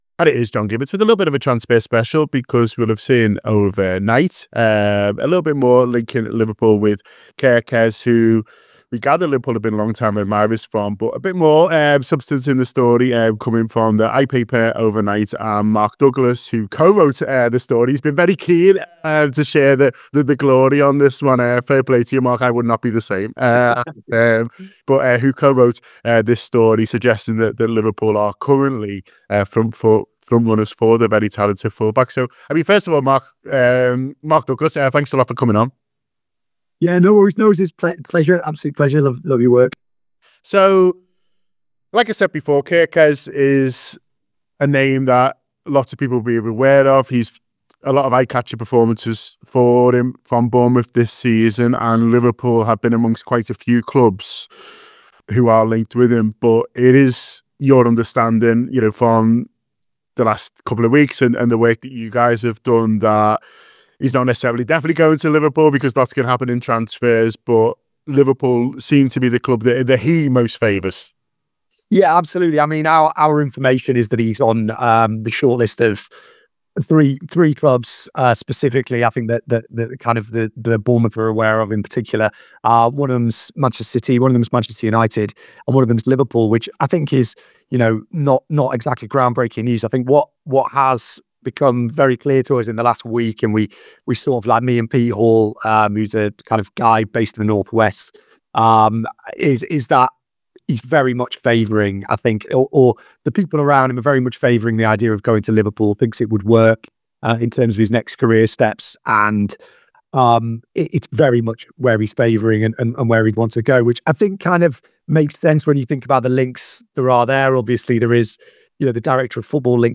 Below is a clip from the show – subscribe for more on Milos Kerkez and other Liverpool transfer news…